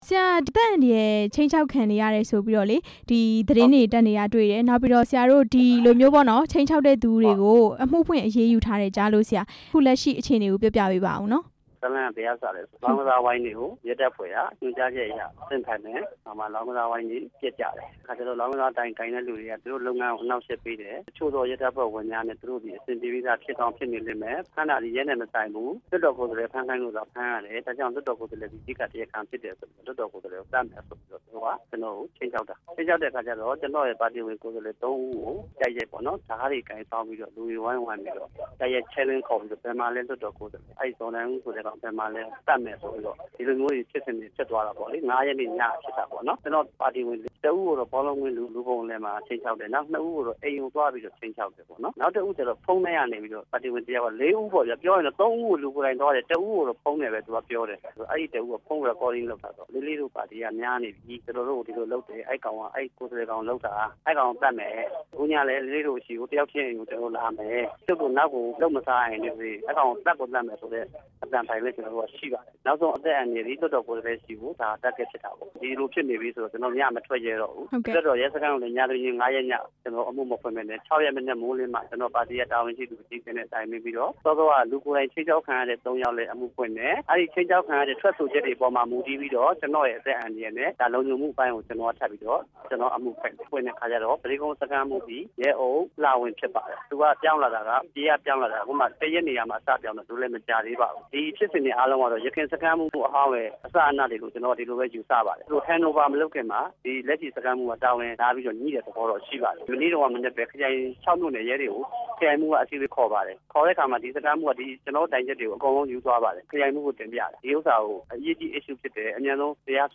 အသက်အန္တရာယ် ခြိမ်းခြောက်ခံရတဲ့ ဦးဇော်နိုင်ဦး နဲ့ မေးမြန်းချက်